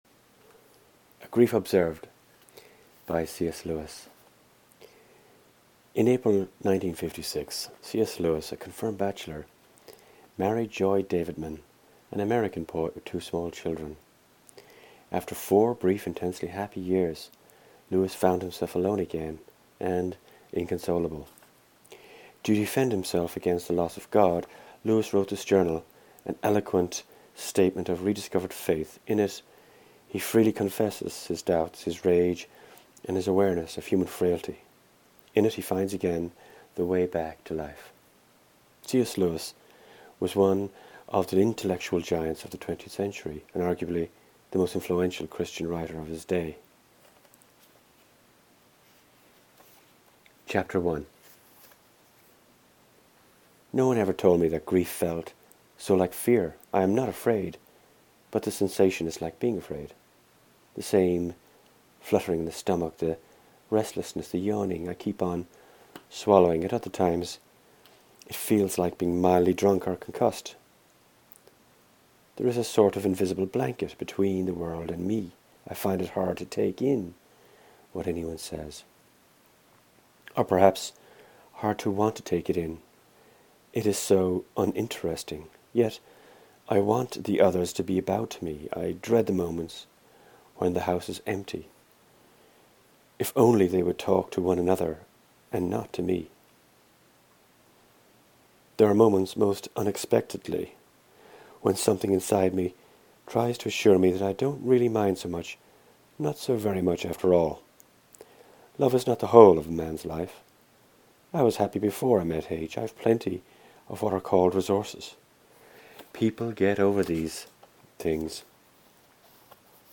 His eyesight is failing & as we could not find an audio book online, we made one.